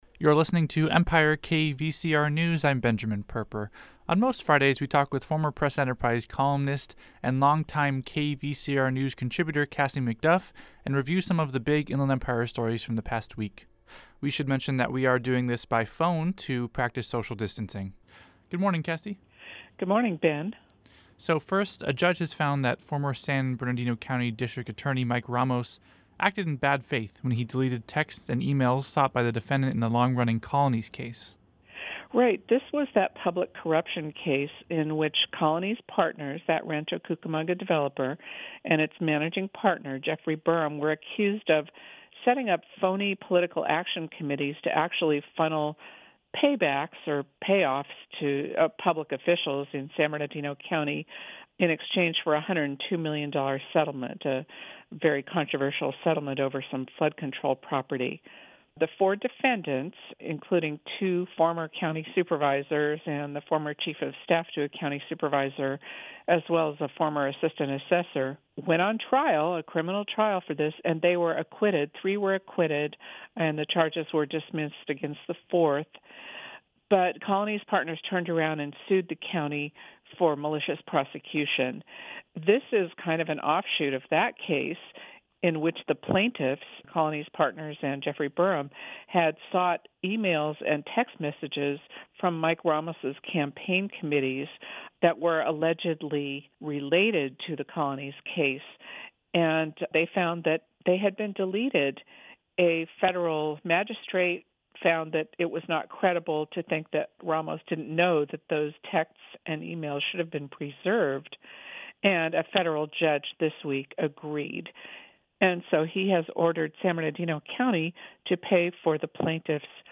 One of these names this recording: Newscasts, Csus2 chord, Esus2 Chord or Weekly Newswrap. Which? Weekly Newswrap